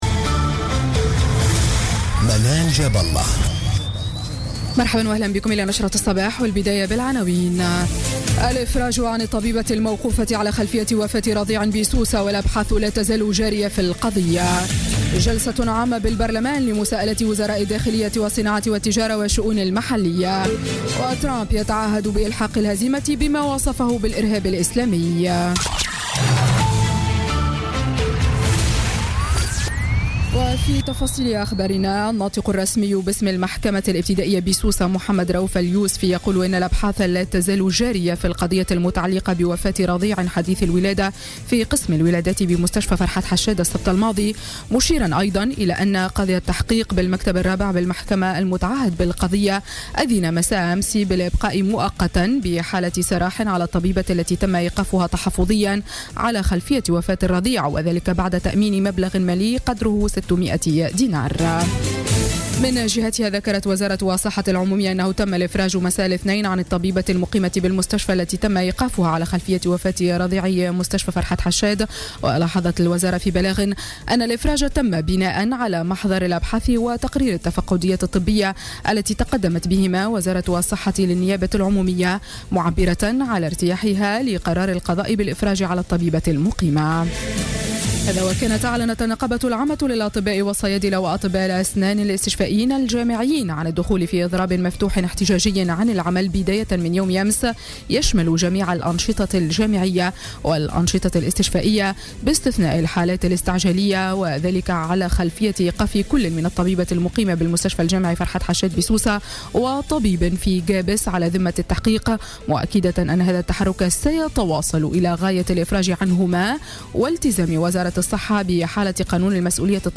نشرة أخبار السابعة صباحا ليوم الثلاثاء 7 فيفري 2017